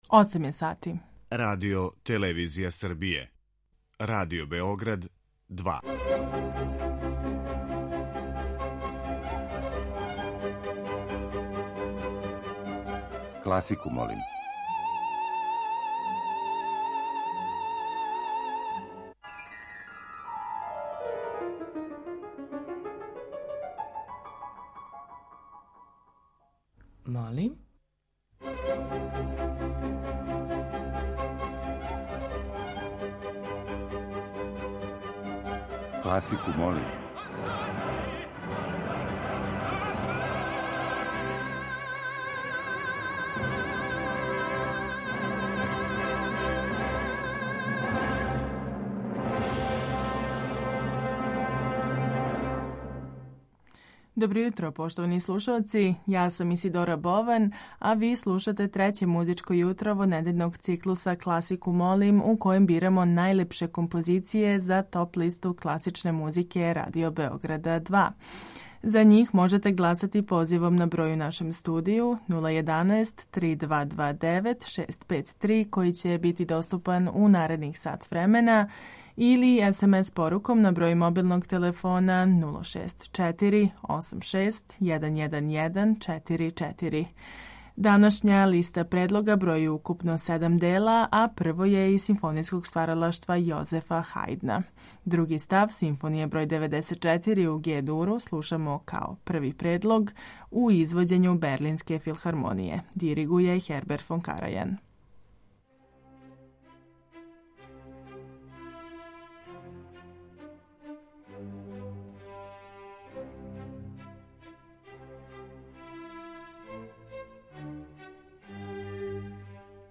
klasika.mp3